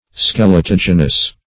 Search Result for " skeletogenous" : The Collaborative International Dictionary of English v.0.48: Skeletogenous \Skel`e*tog"e*nous\, a. [Skeleton + -genous.] Forming or producing parts of the skeleton.
skeletogenous.mp3